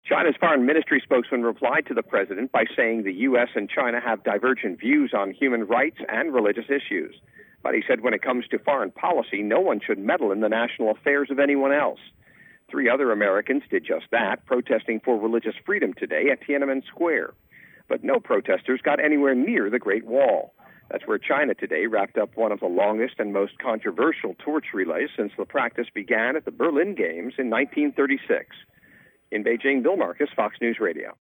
2008 Beijing Olympics, China, Fox News Radio, Selected Reports: